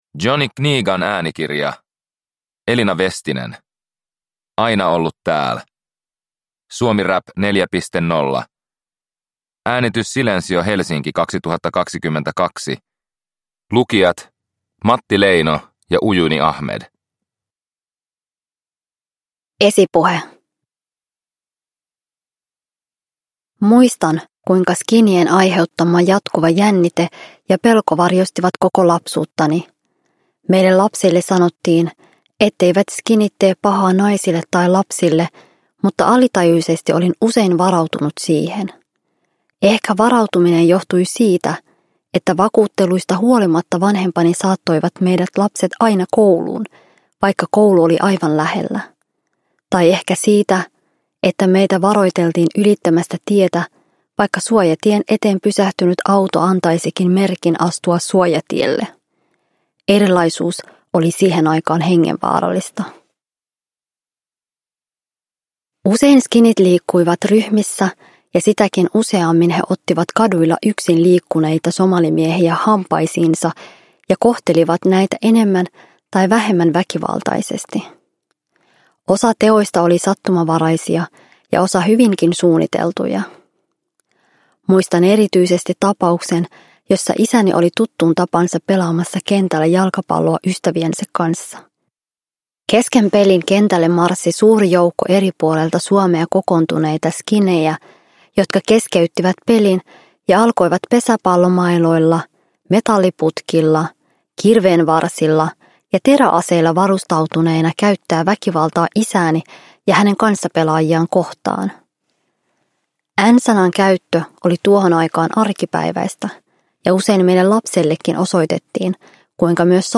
Aina ollu tääl – Ljudbok – Laddas ner